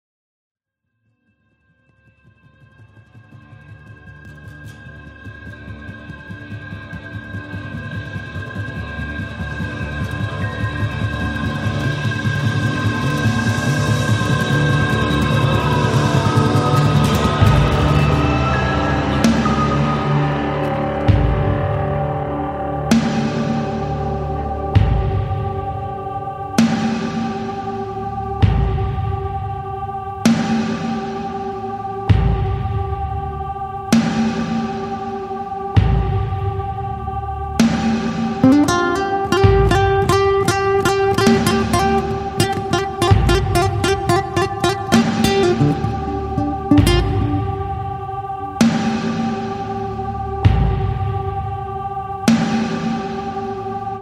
Aufgenommen am 12.12.04 im Porgy & Bess Wien